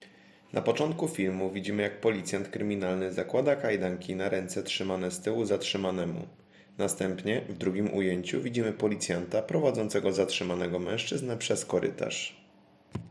Nagranie audio Audiodeskrypcja.mp3